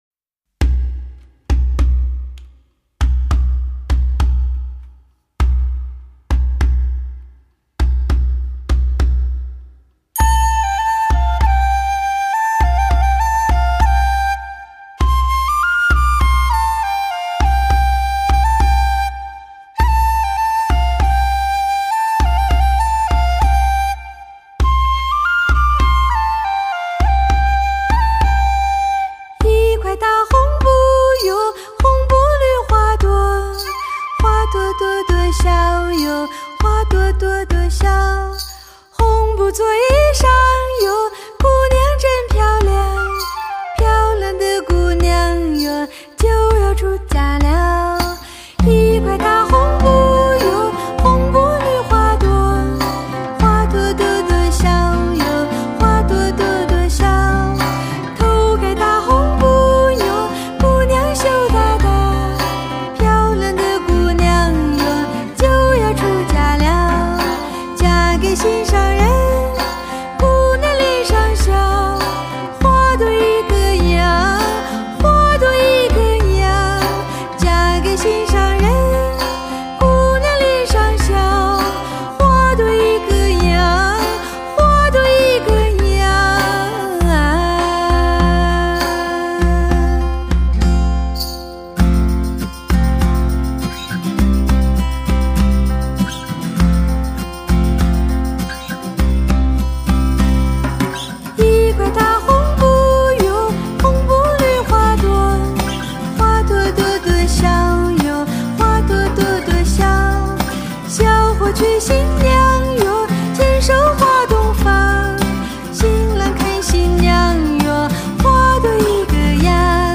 当代民谣传奇女歌手以乐队形式推出的真发烧专辑
城市中的吟唱，传递的却是山谷里的声音，宁静致远。